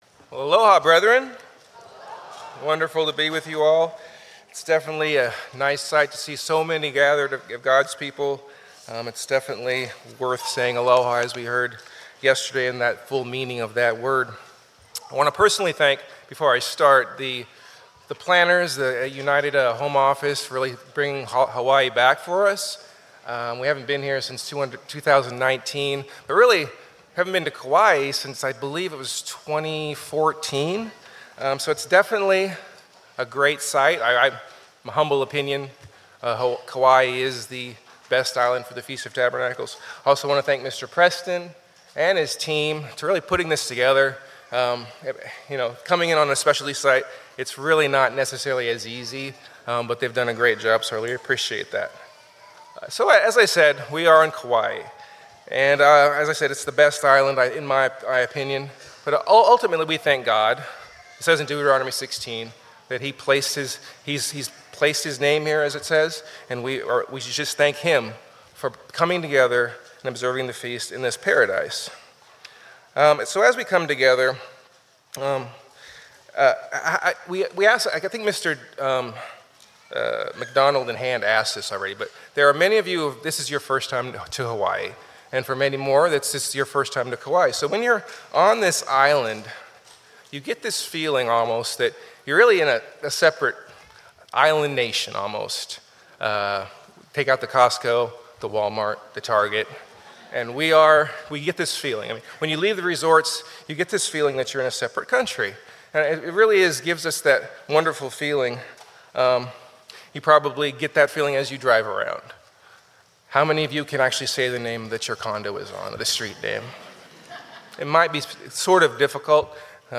Given in Lihue, Hawaii